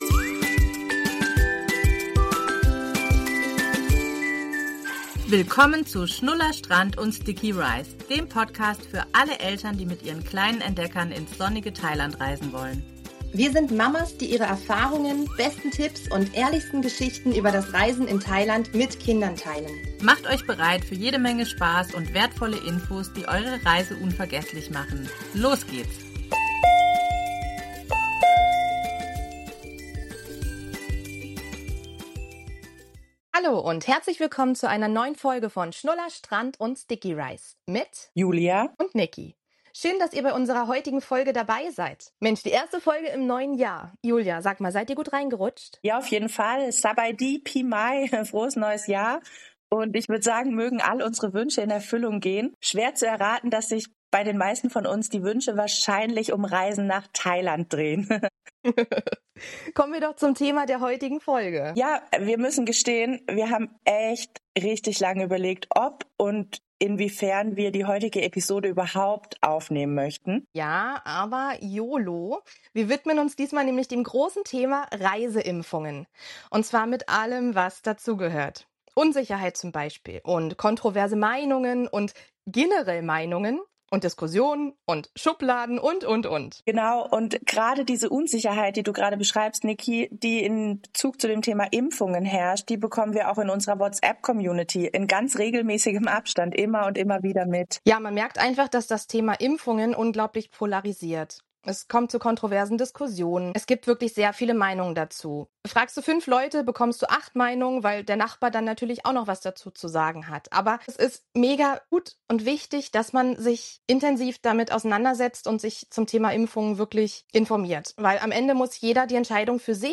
zwei Mamas mit Fernweh, Sonnencreme im Gepäck und ganz viel Herz für Thailand.